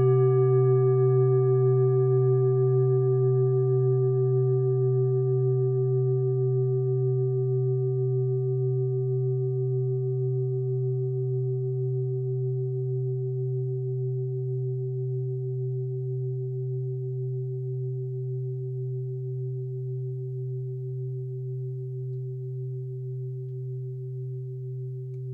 Klangschalen-Typ: Tibet
Klangschale Nr.4
Gewicht = 2020g
Durchmesser = 26,0cm
(Aufgenommen mit dem Filzklöppel/Gummischlegel)
klangschale-set-6-4.wav